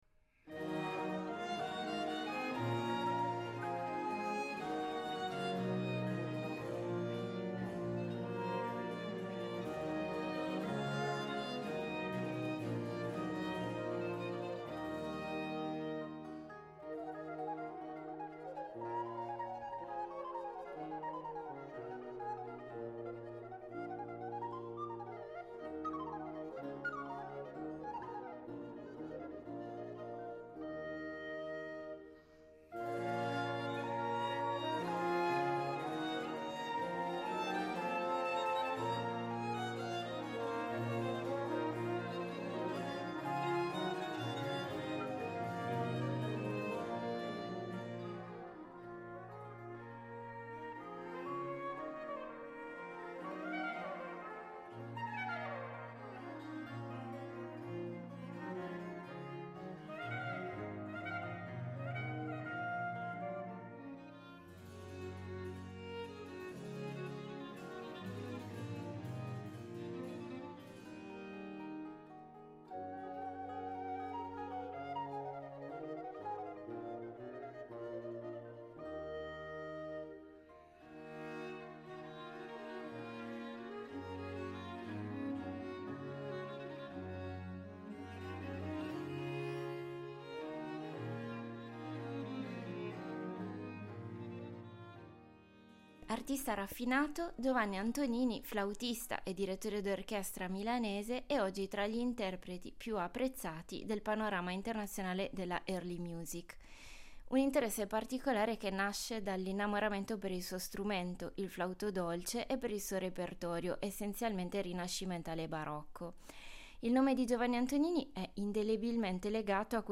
Lo abbiamo incontrato nel ridotto dei palchi del Teatro alla Scala di Milano per tracciare un ritratto d’artista ripercorrendo le tappe della sua carriera dalla fondazione dell’ensemble che dirige, con i sui vari progetti e le molteplici collaborazioni, passando per le sue varie vesti di solista, direttore d’orchestra e direttore artistico.